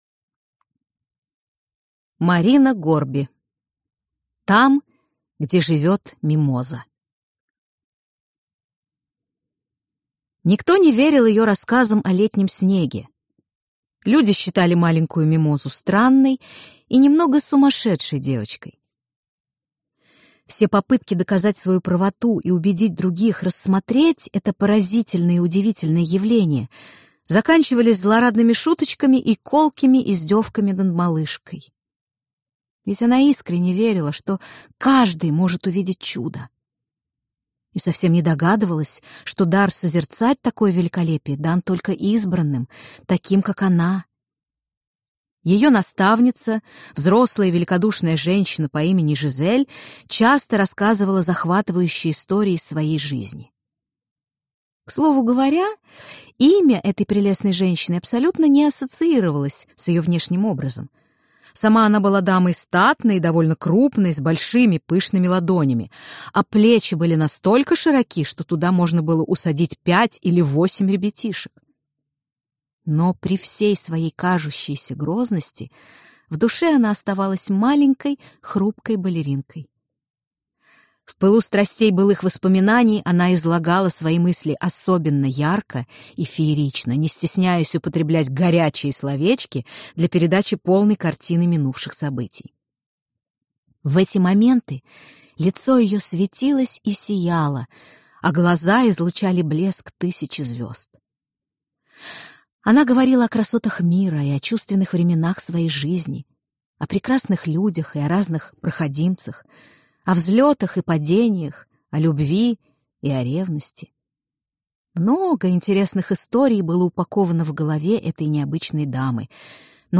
Аудиокнига Там, где живёт Мимоза | Библиотека аудиокниг